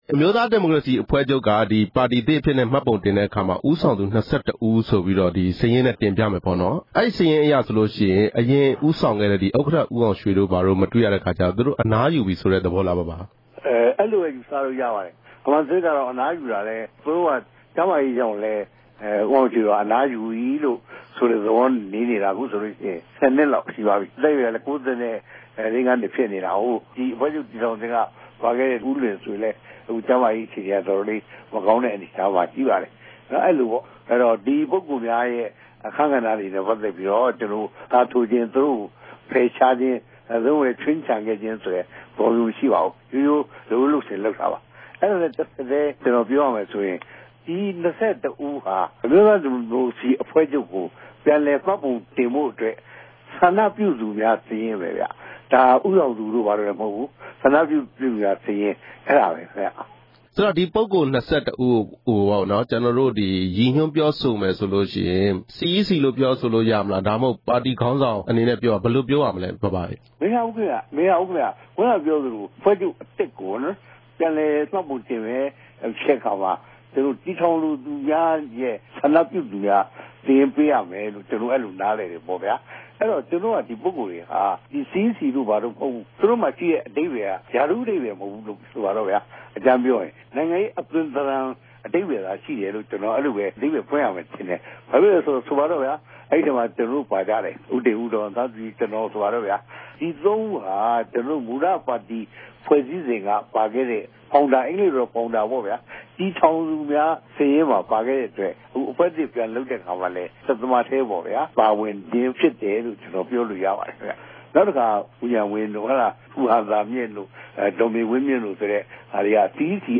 မှတ်ပုံတင်မယ့် NLD အနေအထား ဦးဝင်းတင်ကို မေးမြန်းချက်